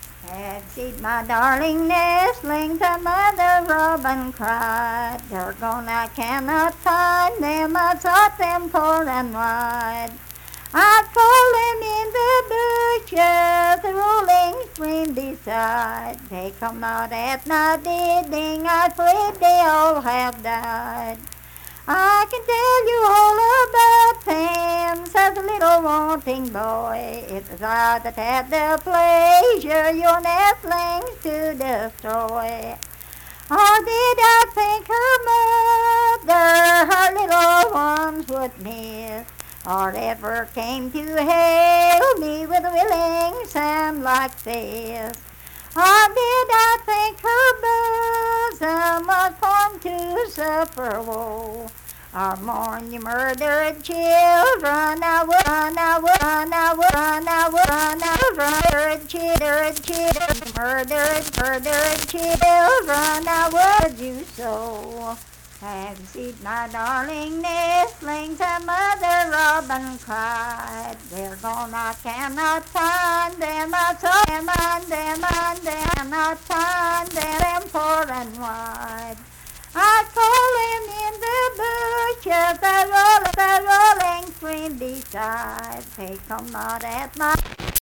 Unaccompanied vocal music performance
Verse-refrain 5(4).Born February 9, 1874 in Wayne County, West Virginia.
Voice (sung)